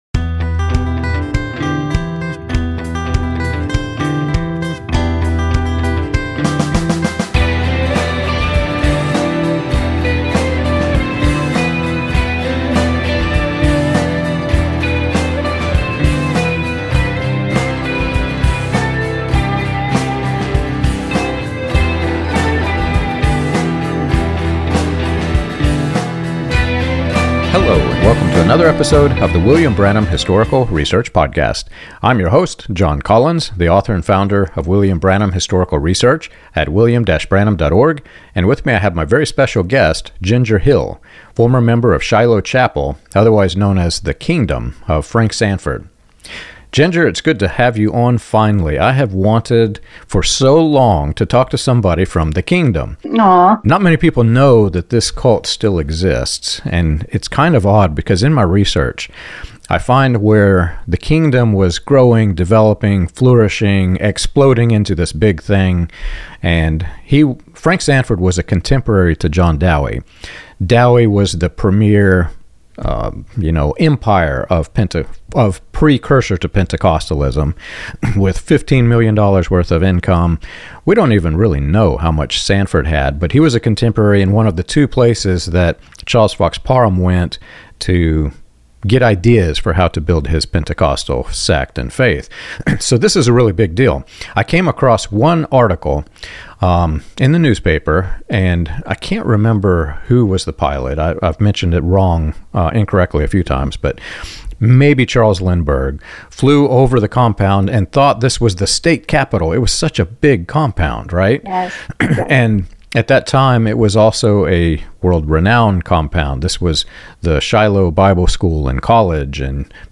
This conversation examines the psychological impact of high-control religious environments, the difference between the historic Christian gospel and performance-based religion, and the subtle warning signs that can help others avoid similar experiences.